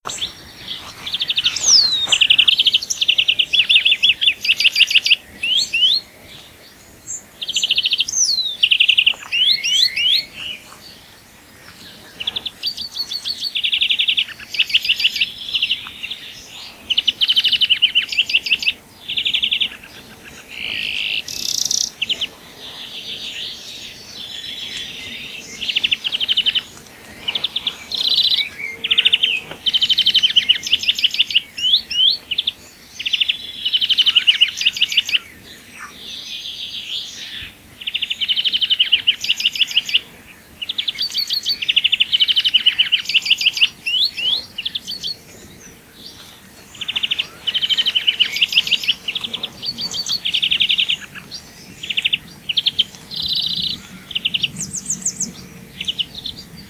groenling
🔭 Wetenschappelijk: Chloris chloris
♫ zang
groenling_zang.mp3